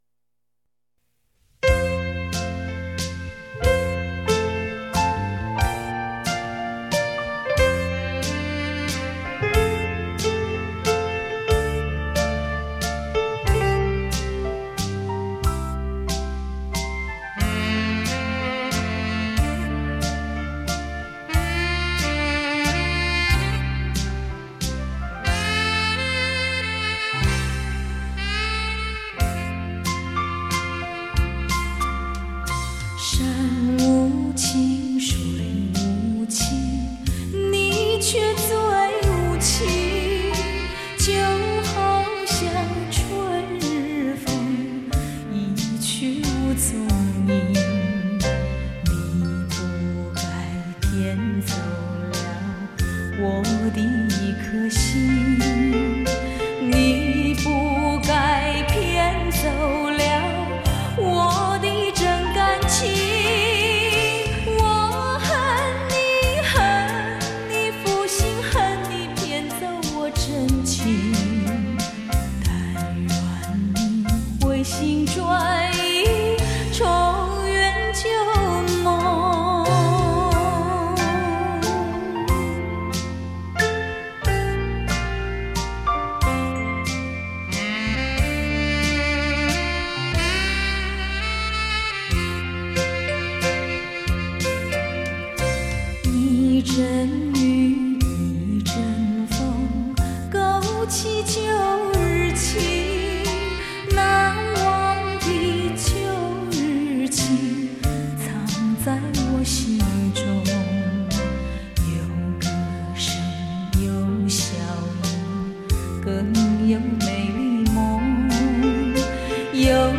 华尔兹